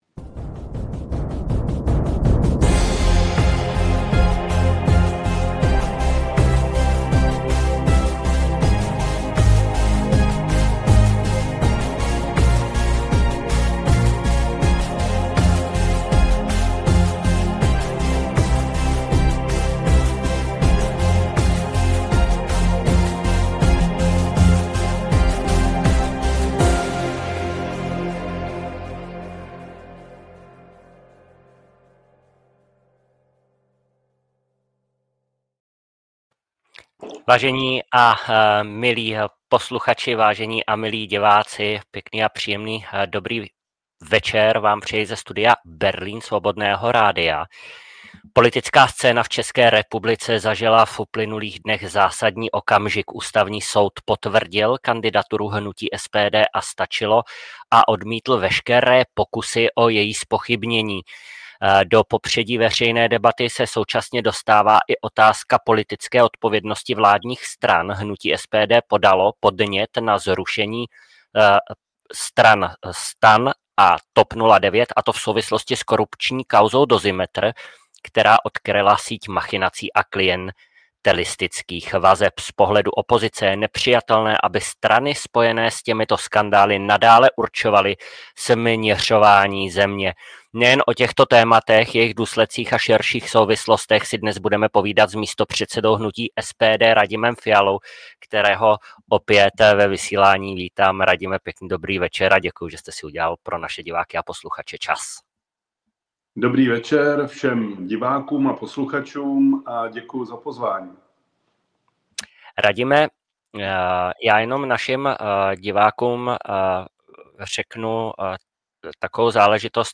2025-09-25-Studio Berlín – Radim Fiala (SPD) – Předvolební rozhovor – SPECIÁL.